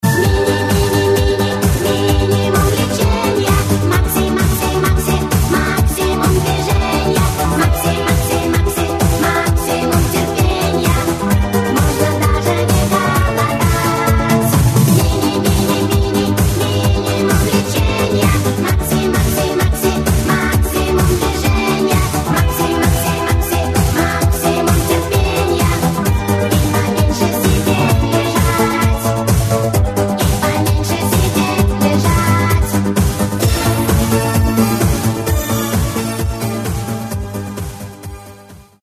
• Качество: 128, Stereo
смешные
ретро
дискотека 80-х